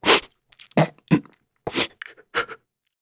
Crow-Vox_Sad.wav